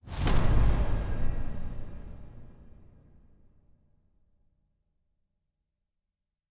Fantasy Interface Sounds
Special Click 15.wav